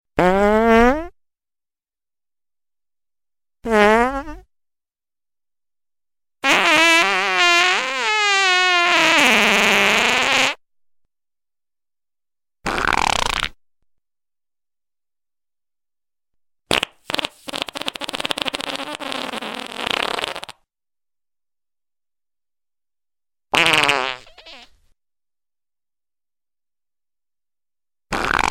3D Tones